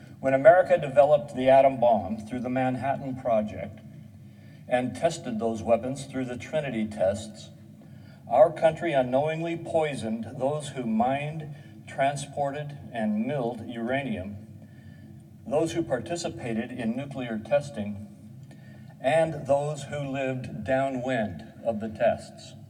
WASHINGTON, D.C. – U.S. Senator Mike Crapo (R-Idaho) delivered remarks on the Senate floor to express his disappointment after updates to the Radiation Exposure Compensation Act were not included in the conference report of the Fiscal Year 2024 National Defense Authorization Act.